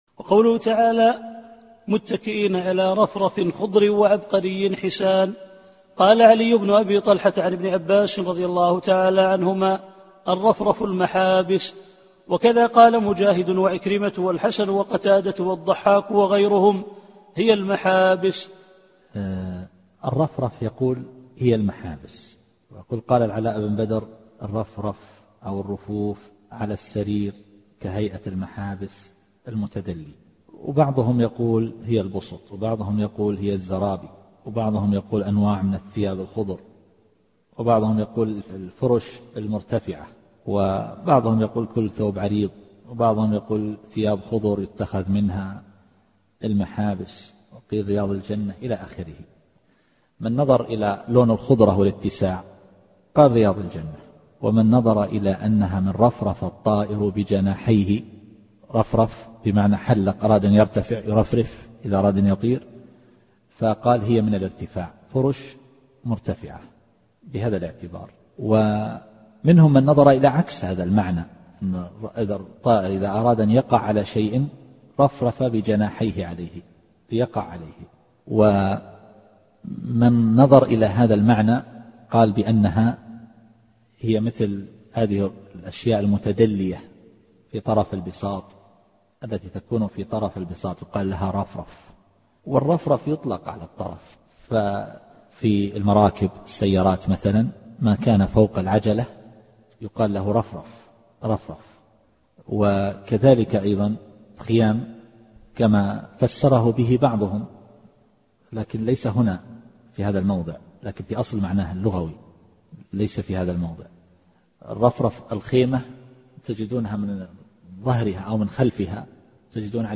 التفسير الصوتي [الرحمن / 76]